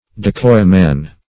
decoy-man - definition of decoy-man - synonyms, pronunciation, spelling from Free Dictionary Search Result for " decoy-man" : The Collaborative International Dictionary of English v.0.48: Decoy-man \De*coy"-man`\, n.; pl.